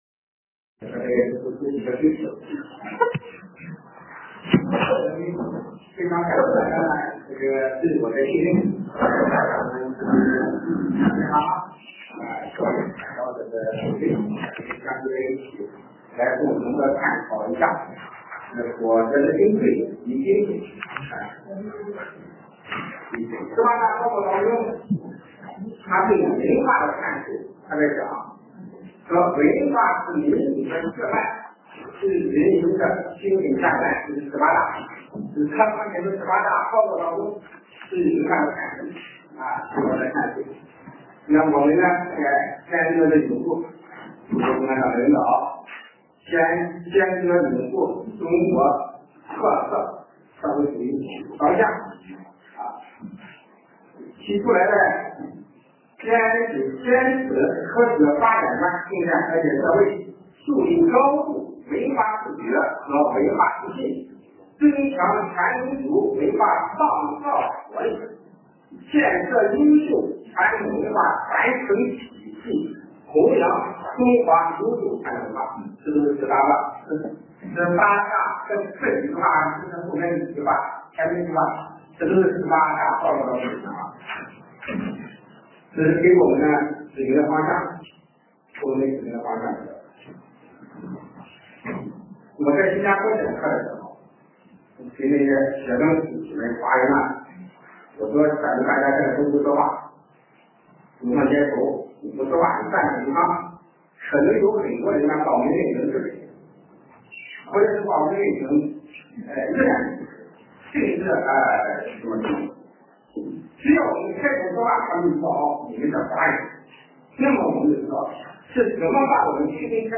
风水教程
风水课程